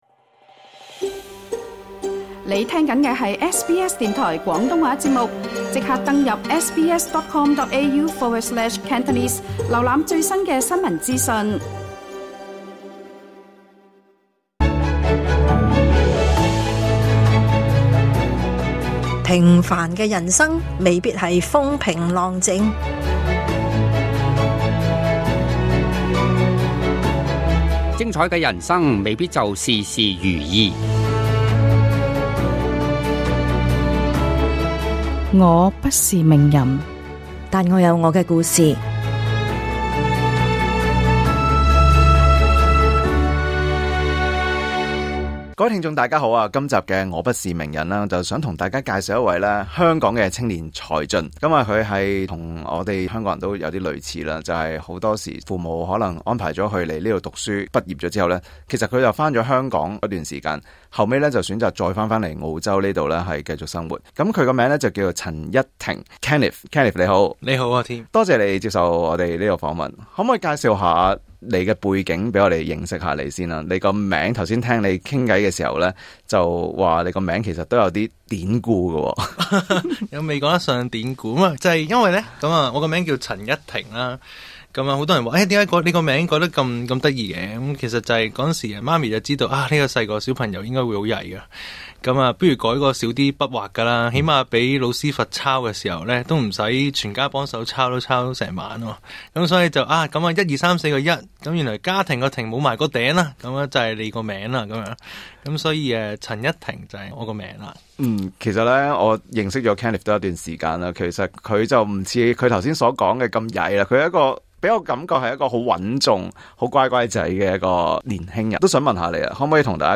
在今次專訪中，他亦分享了他重新塑造這個品牌時，特意請來香港插畫師設計包裝，以及他對日後在這裡發展的盼望。